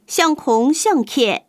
Hong/Hakka_tts